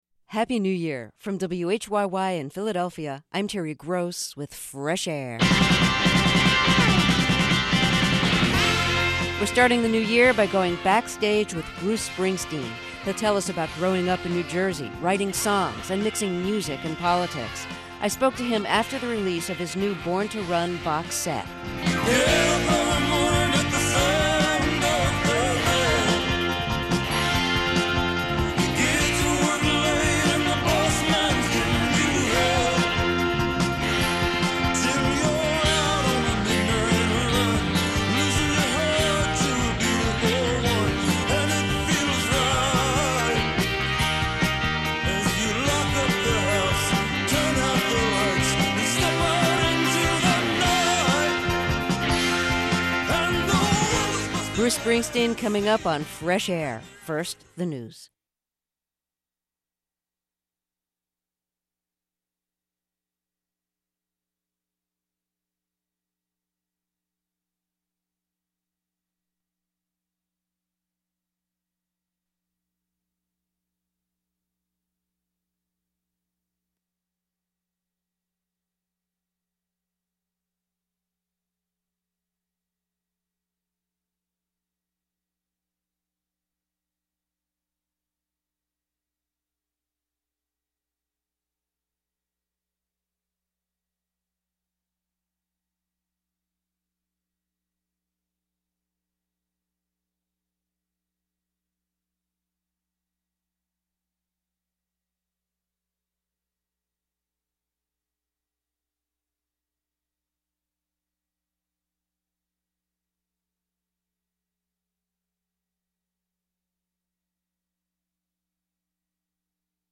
This interview originally aired on Nov. 15, 2005.